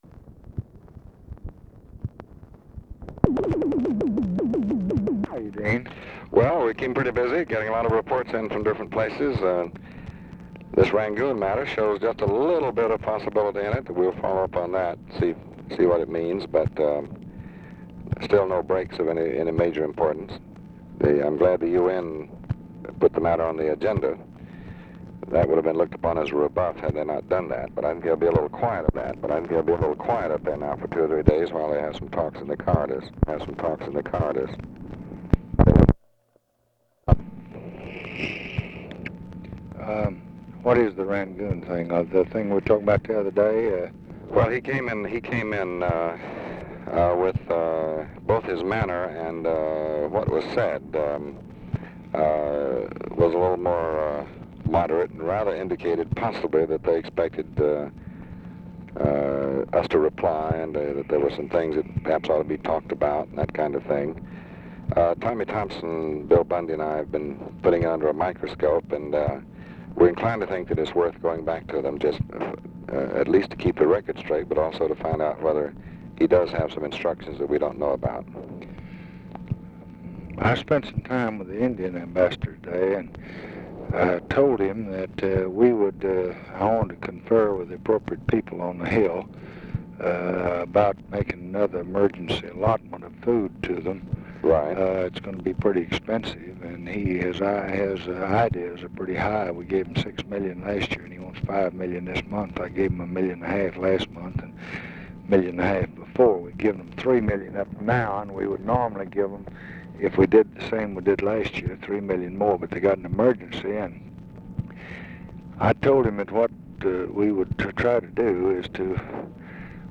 Conversation with DEAN RUSK, February 2, 1966
Secret White House Tapes